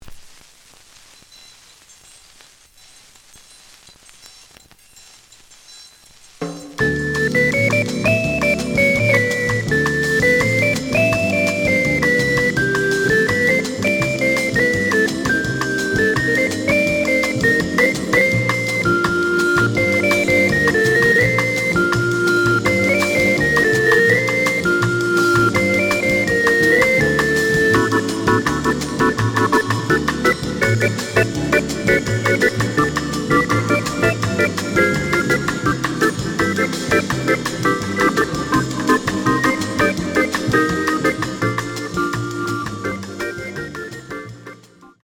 The audio sample is recorded from the actual item.
●Genre: Latin Jazz
Looks good, but some noise on beginning of both sides.